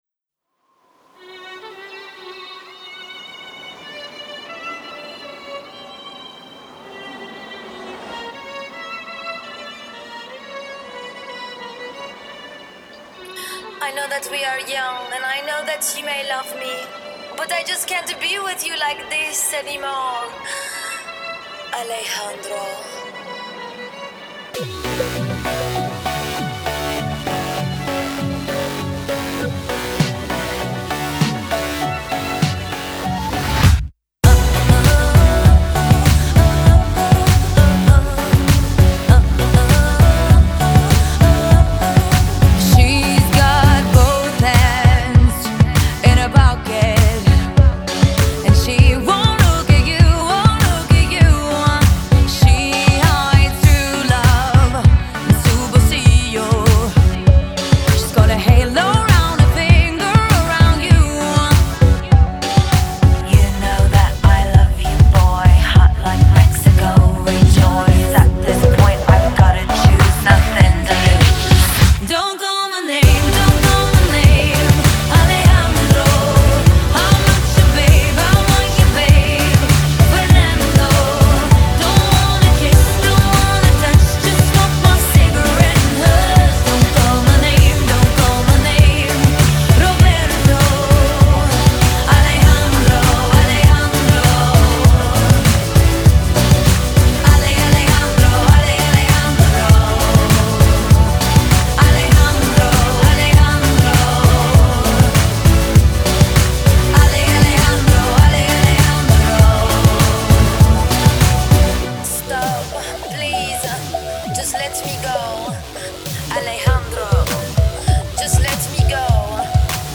Категория: Популярная музыка